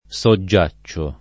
soggiacere [SoJJa©%re; non SoJJ#-] v.; soggiaccio [